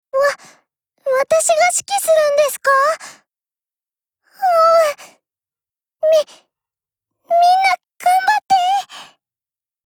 Cv-30154_warcry.mp3